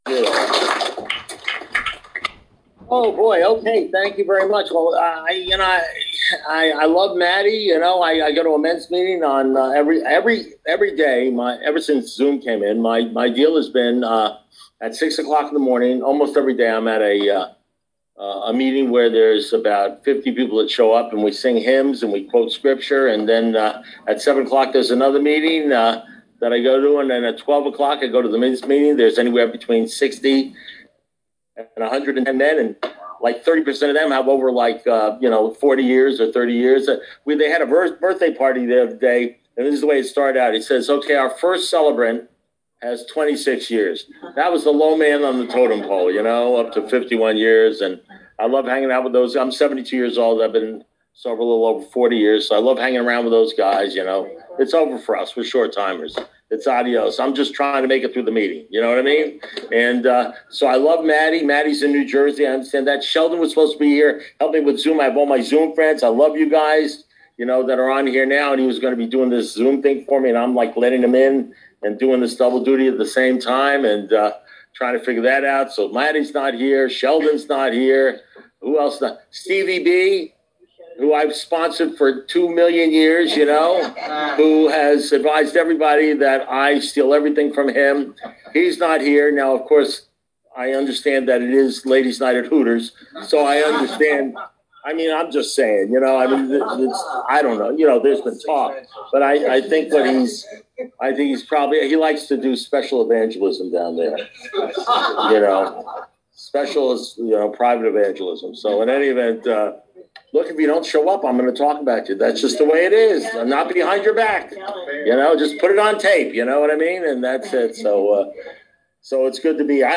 at the Golden Text Group, Dania FL